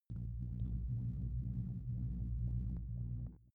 Gemafreie Sounds: Raumschiff